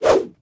footswing9.ogg